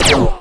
fire_laser2.wav